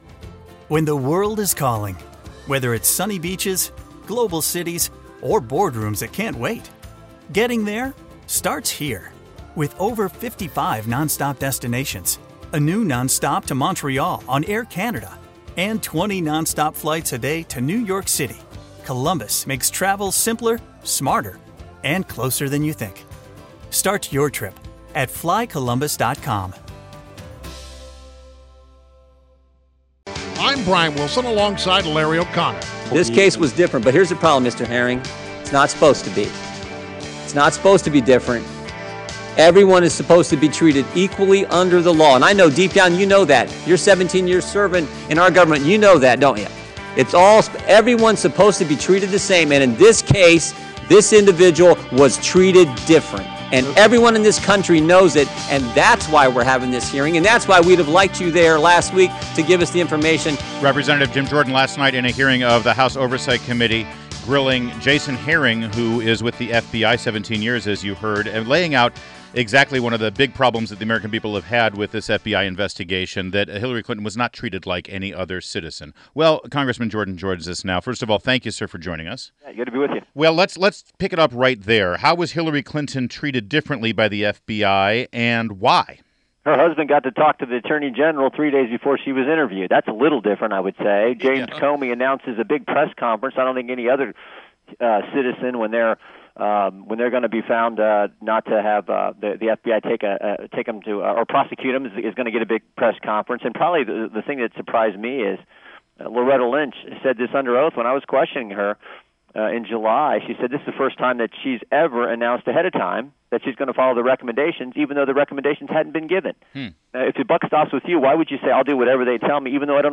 WMAL Interview - REP. JIM JORDAN - 09.13.16